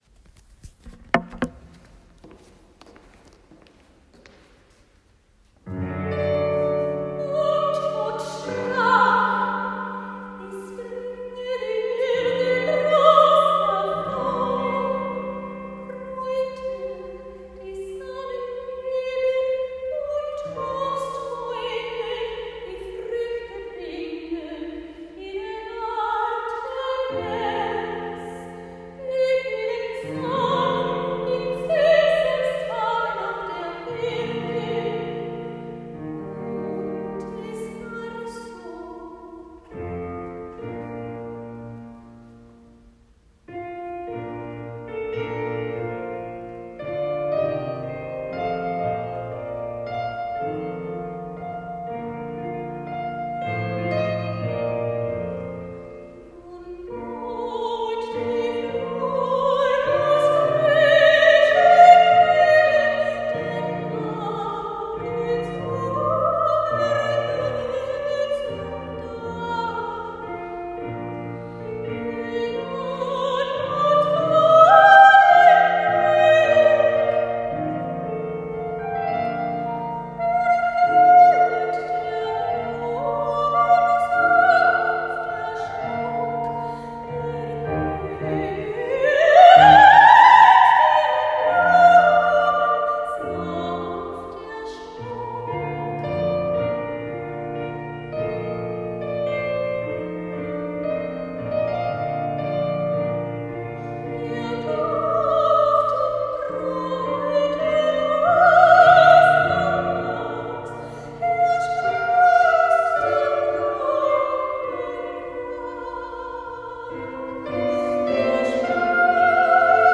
Soprano
Piano; Pfarrzentrum St. Paul Benefit Concert for the Children in Ukraine, June 17, 2023